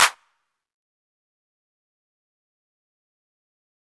Sharp Clap.wav